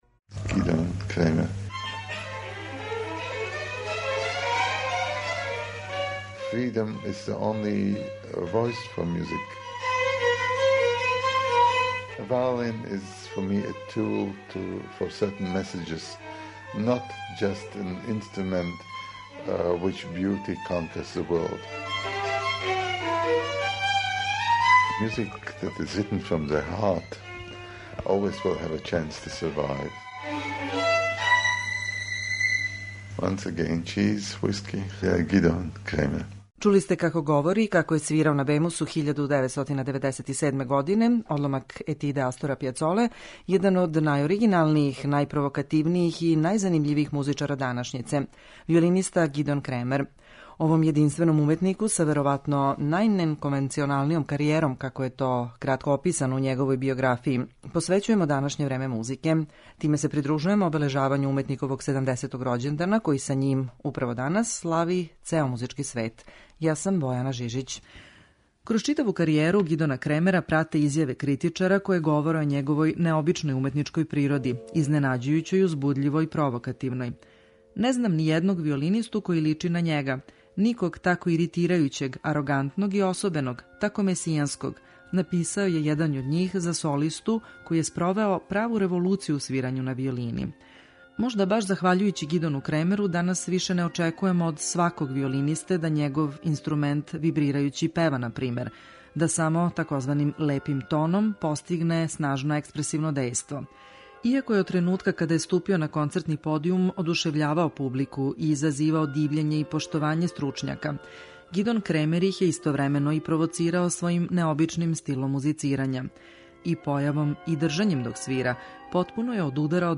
Гидона Кремера ћемо представити и кроз одломке интрвјуа сниманих са овим славним солистом у периоду од 1994. до 2007. године, када је последњи пут гостовао у Београду.